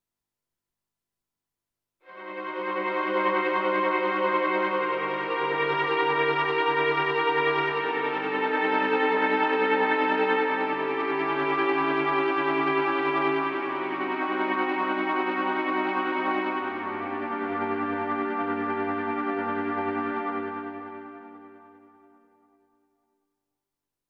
15. I SUONI - GLI STRUMENTI XG - GRUPPO "STRINGS"
06. Slw At Trem Str.
XG-05-06-SlwAtTremStr.mp3